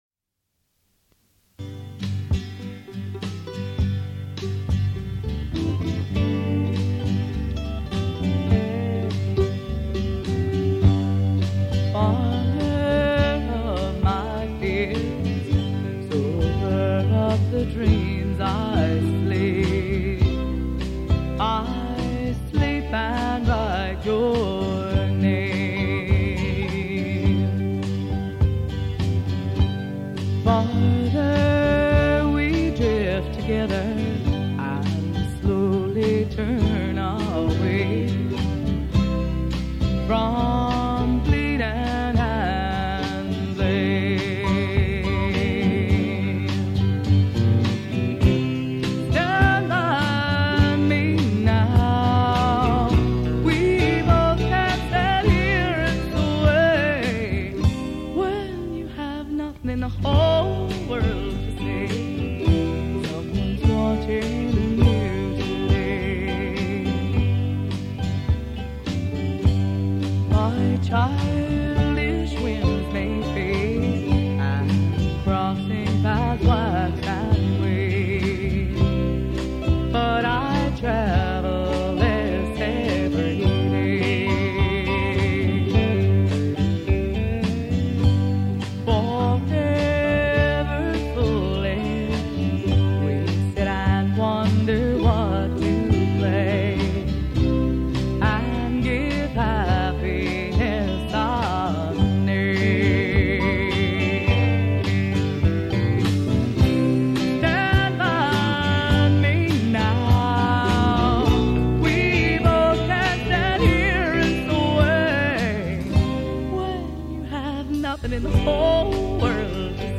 mandolin
acoustic guitar
electric guitar
electric bass
drums